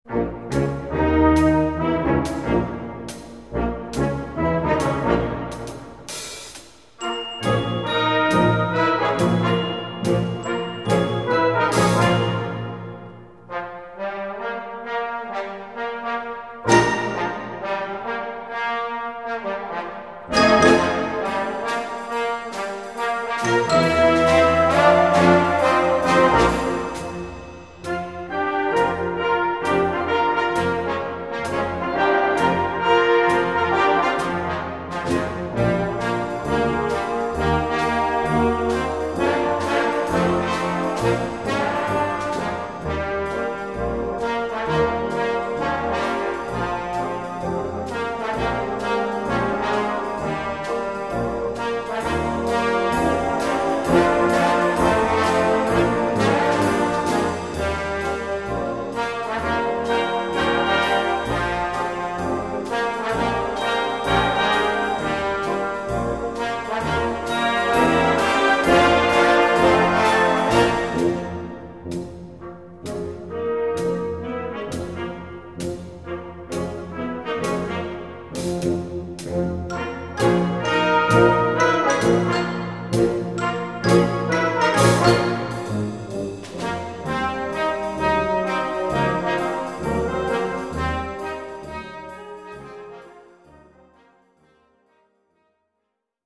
Répertoire pour Harmonie/fanfare - 3 Trombones et Fanfare